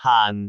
speech
syllable
pronunciation
haan4.wav